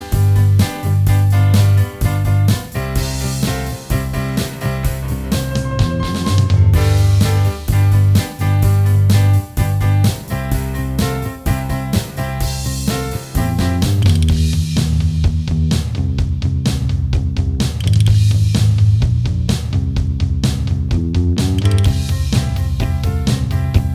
Minus Guitars Rock 3:53 Buy £1.50